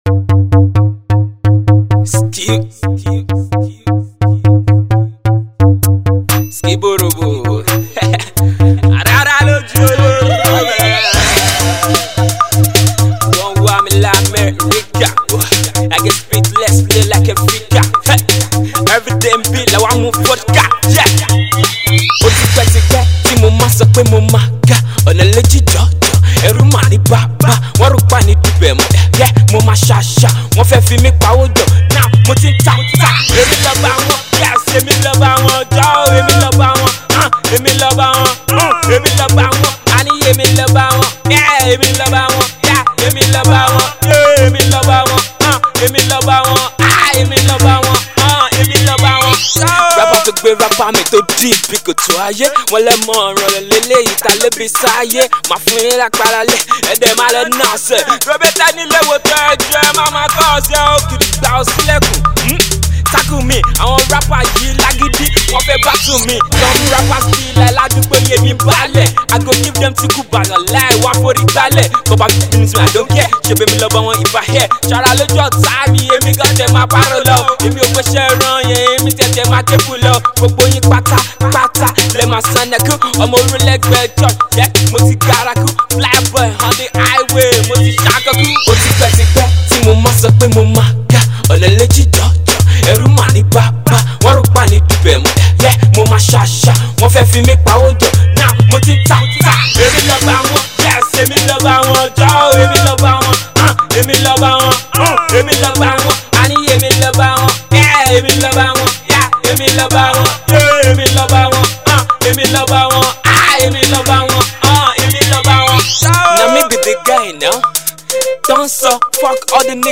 indigenous Hip-Hop, Yoruba Music
Yoruba Rapper
Street Rap single